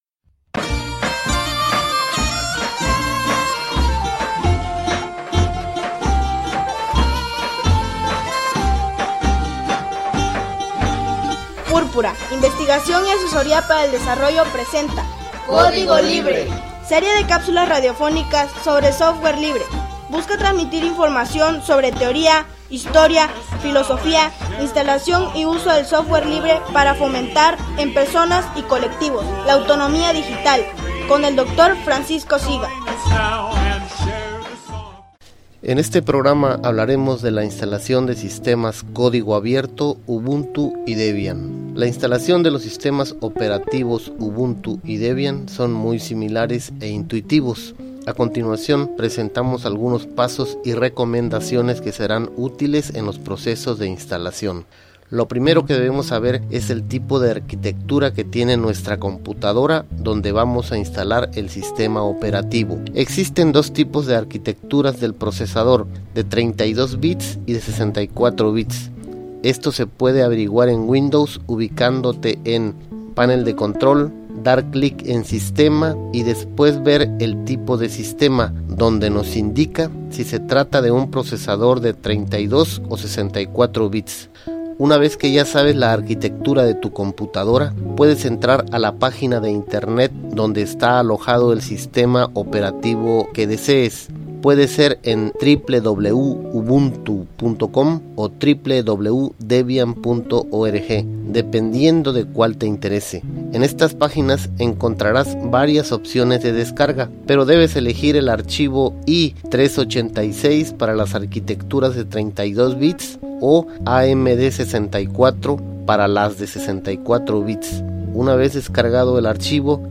Serie de capsulas radiofónicas sobre Software Libre.